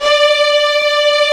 Index of /90_sSampleCDs/Roland L-CD702/VOL-1/CMB_Combos 1/CMB_Arco_Marcato
STR STRING0I.wav